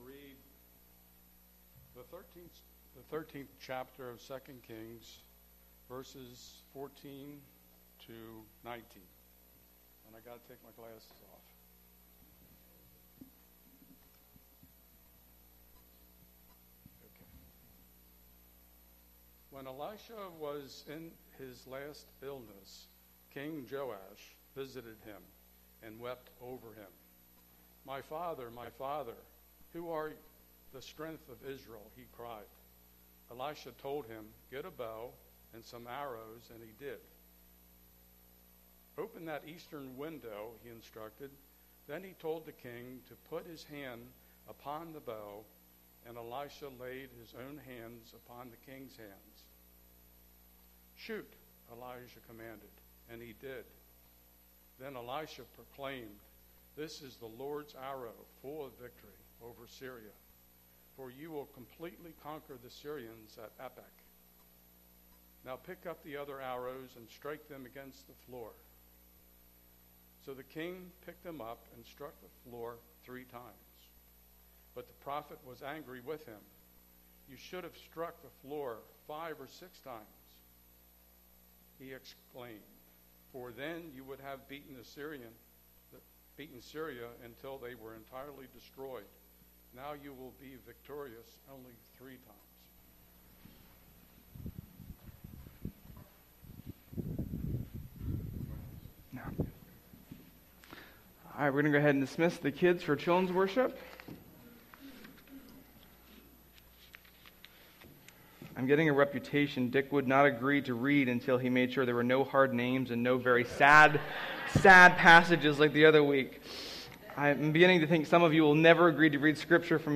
Sermon-3.6.22.mp3